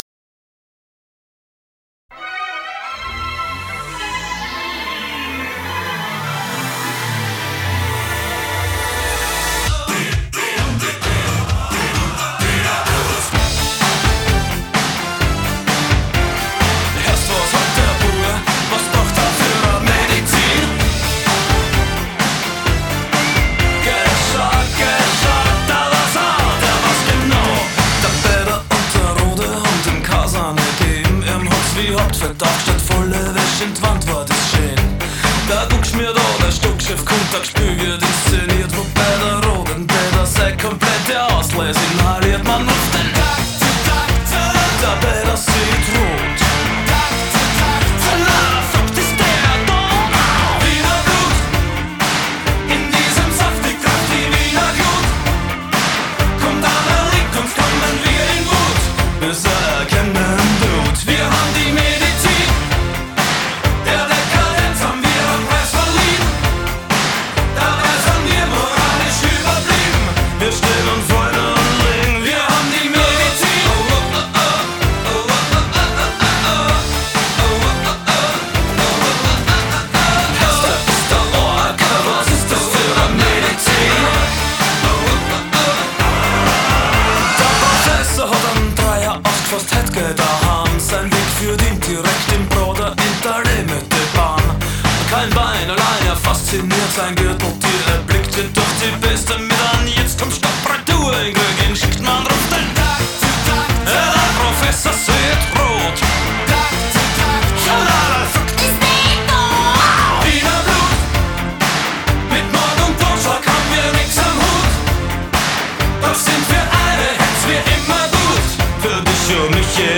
Genre: Rock,New Wave